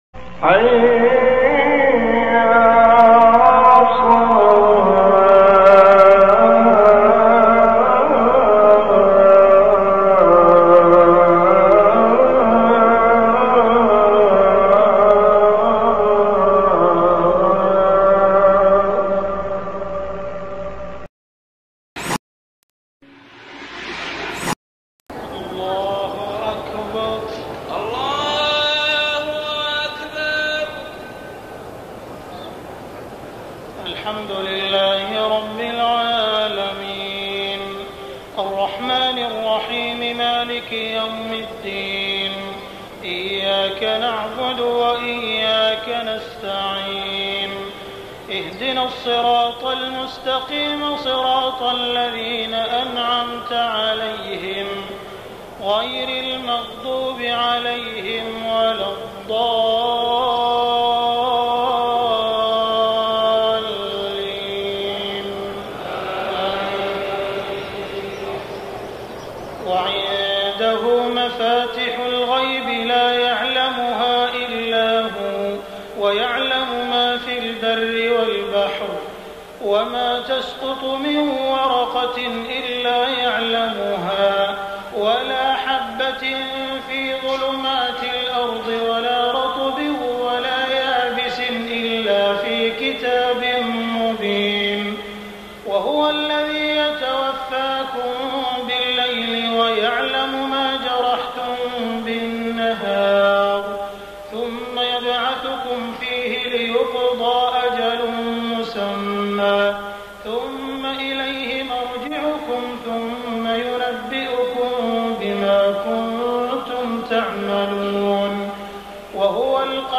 صلاة التهجد رمضان عام 1424هـ من سورة الأنعام > تراويح الحرم المكي عام 1424 🕋 > التراويح - تلاوات الحرمين